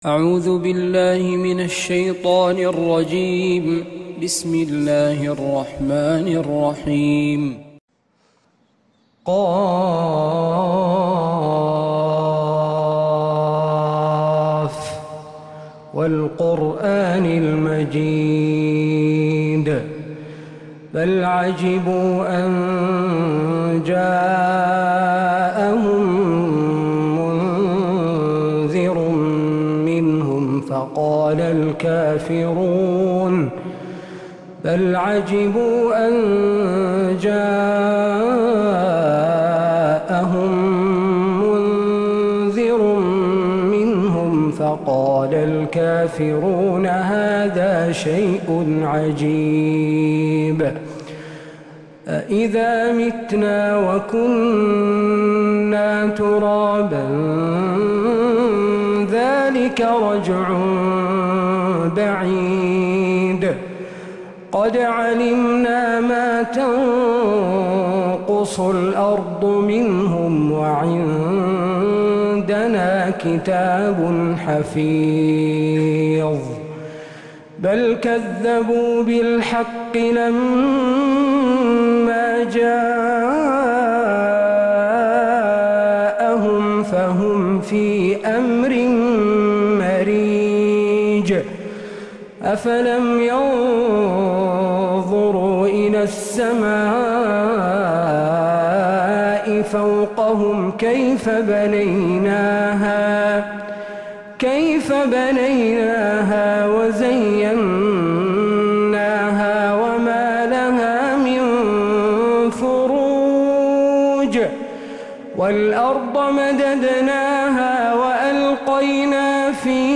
من فجريات الحرم النبوي